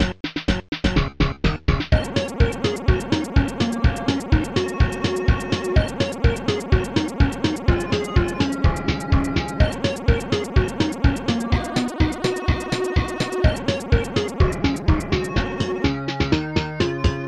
ST-61:4.sax-6b8
ST-54:facekick
ST-54:808-snare
ST-54:shaker